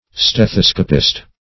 Stethoscopist \Ste*thos"co*pist\